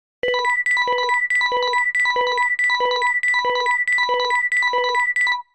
フリー音源効果音「警告音」「緊急音」です。
フリー音源 「警告音」「緊急音」2
緊急音2